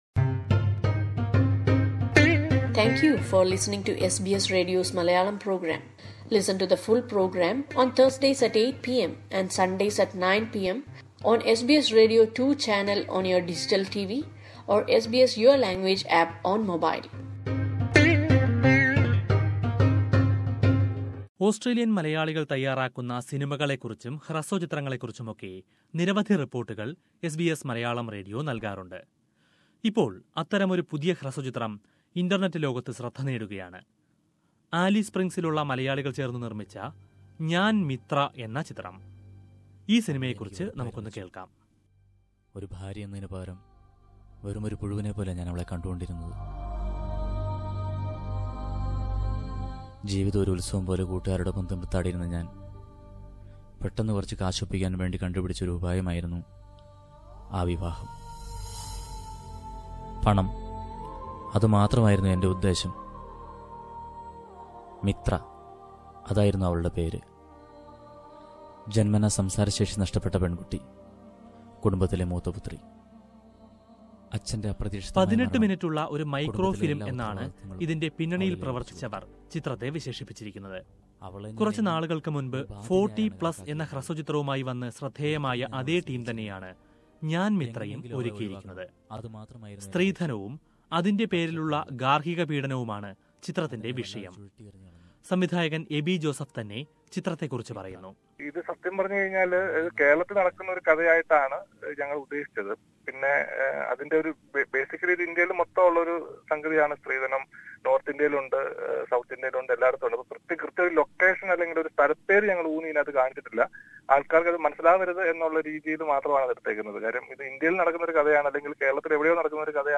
Njan Mitra (I am Mitra) is a new short film produced by a group of Malayalees in Alice Springs. A film which looks into the dowry issue in Indian society, is getting good review online. Listen to a report about that.